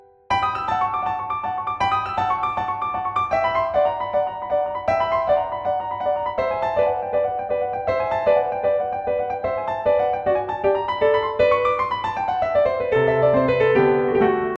例えば第１楽章中間部、華やかな右手がコロコロ奔放に、そして微妙に音を変化させて推移する箇所があります。